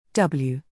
Aussprache des englischen Alphabets
J Dschey W Dabbelju
W - /ˈdʌbljuː/